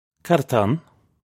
Pronunciation for how to say
Kad atah unn? (U)
This is an approximate phonetic pronunciation of the phrase.